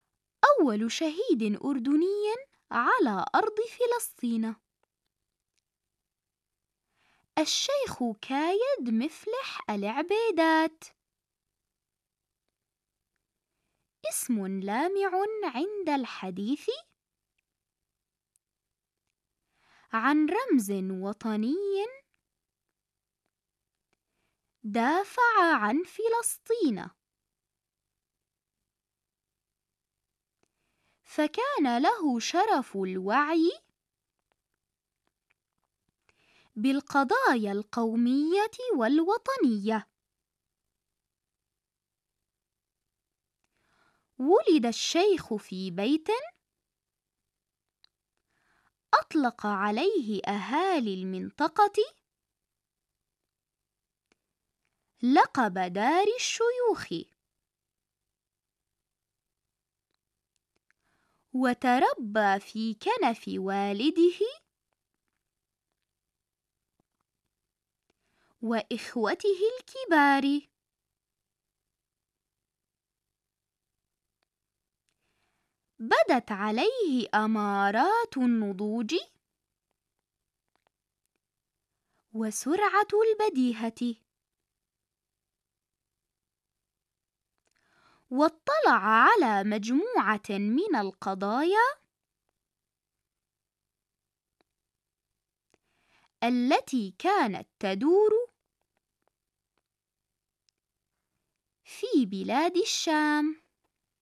نص املاء اول شهيد اردني mp3 تمارين عربي صف خامس فصل اول منهاج اردني 2024/2025